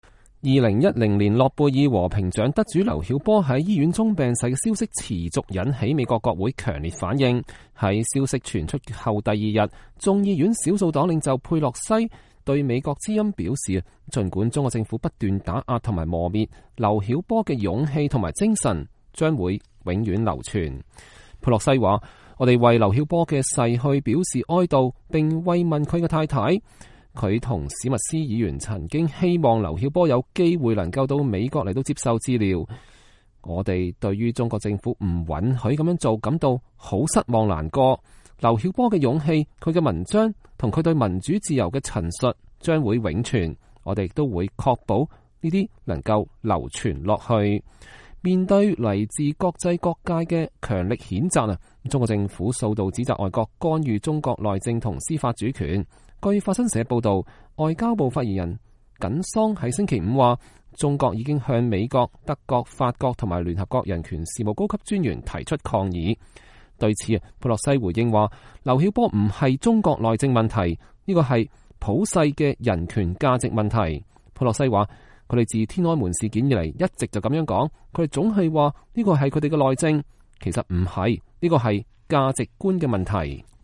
眾議院少數黨領袖佩洛西接受美國之音採訪